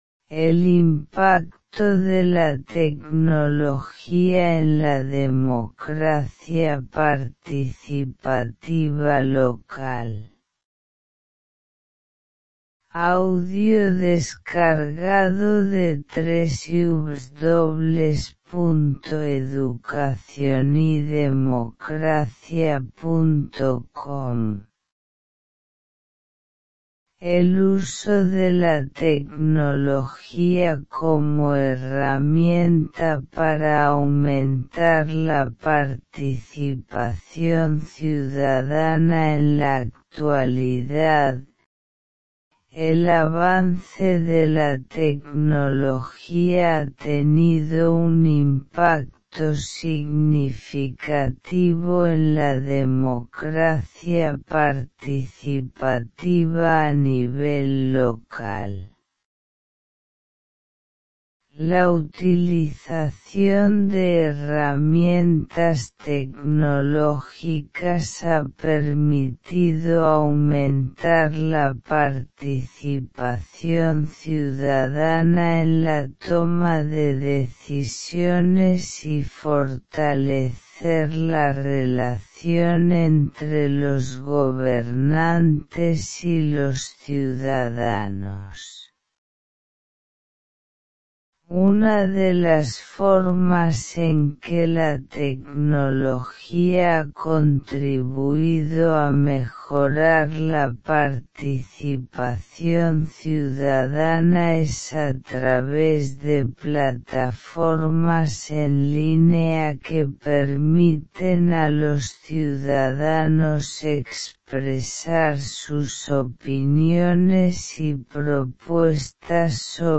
Descarga este artículo en formato de audio y accede a la información sin dificultades.